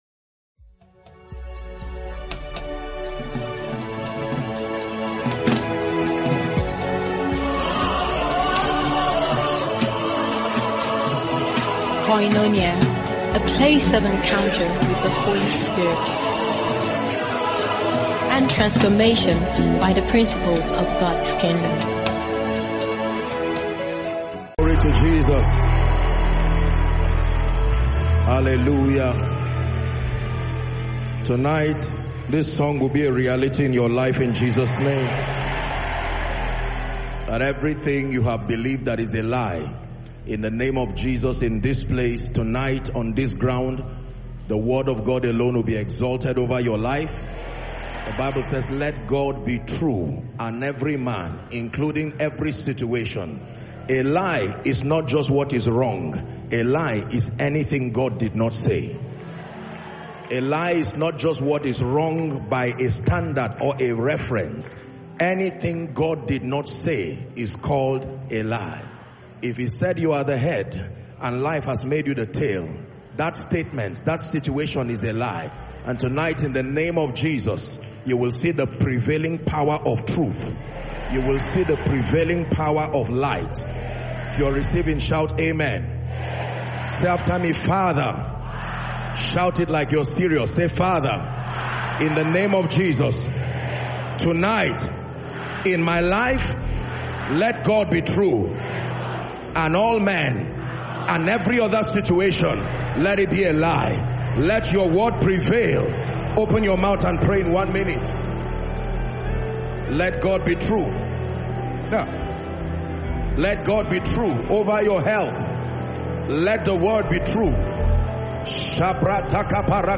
From the opening worship to the moment of prophetic release, the presence of God was tangible.
Deep Revelatory Teaching – Insightful exposition of Scripture that built faith and stirred spiritual hunger.